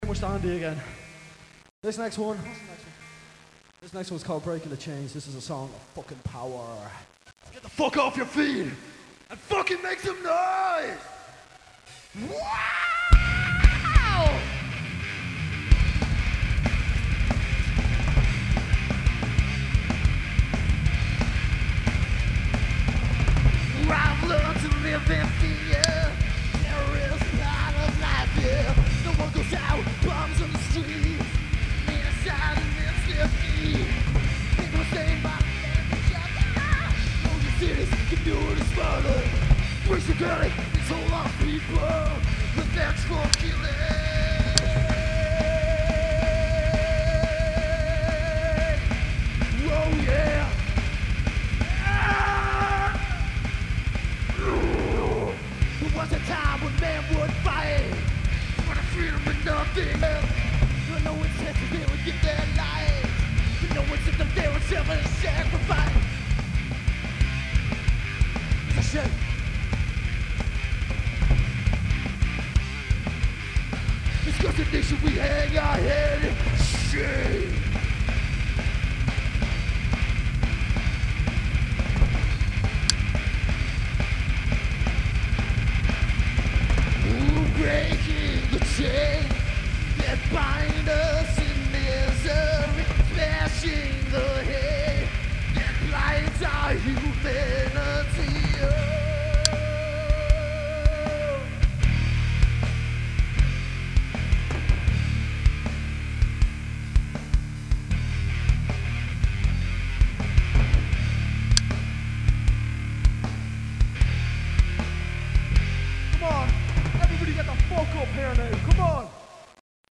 Recorded live in the Rosetta, Belfast.